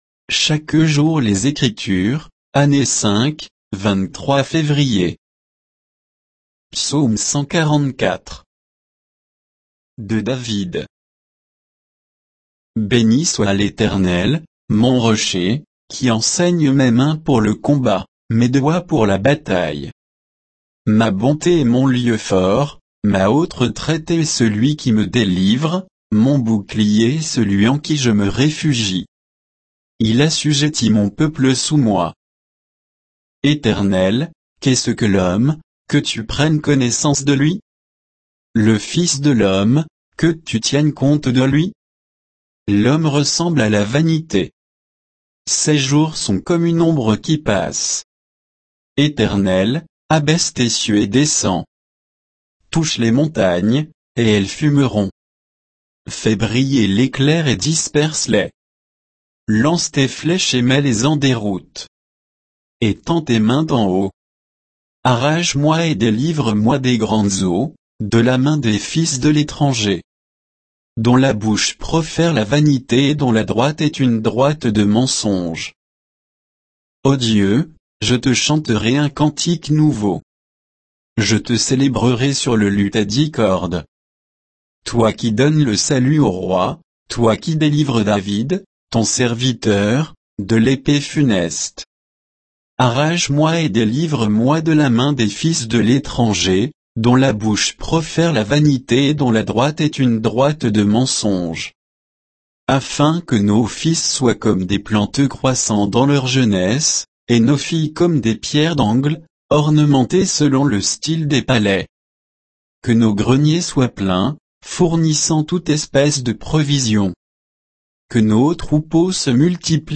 Méditation quoditienne de Chaque jour les Écritures sur Psaume 144